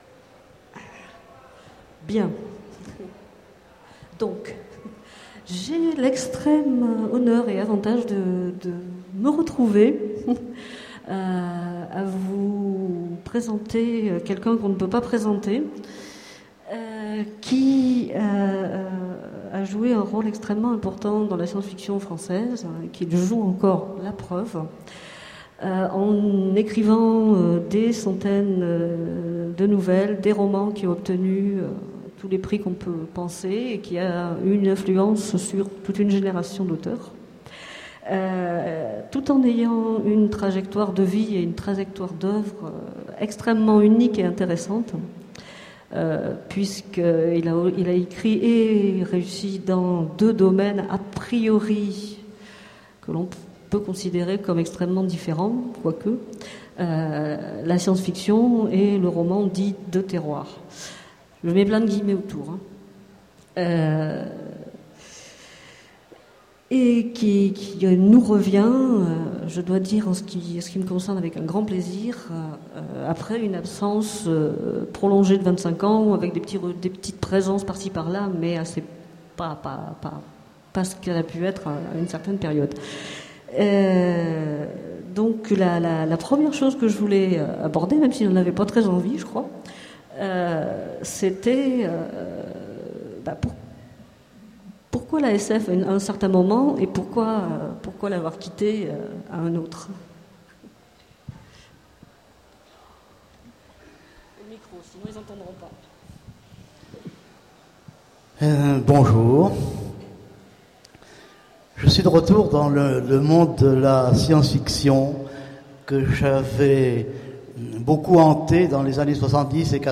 Voici l'enregistrement de la rencontre avec Michel Jeury aux Utopiales 2010.
Utopiales 2010 : conférence rencontre avec Michel Jeury